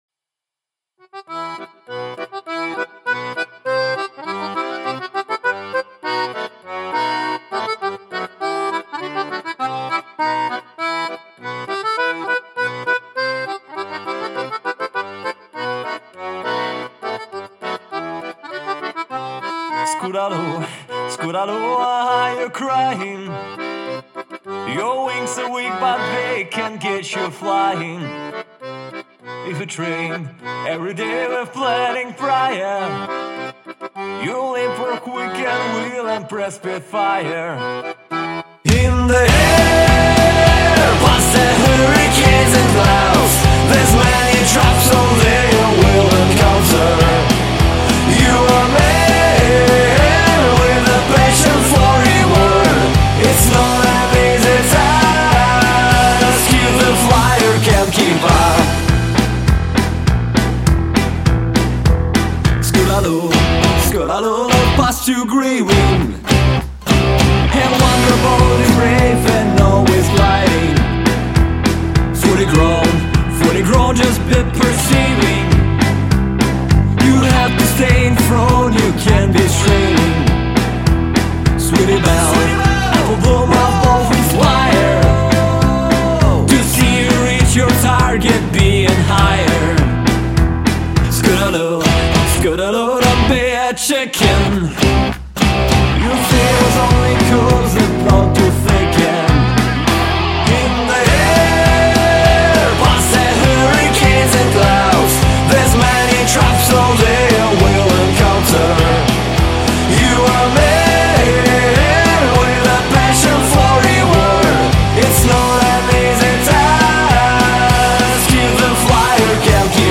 Original parody-song
Vocals, Guitars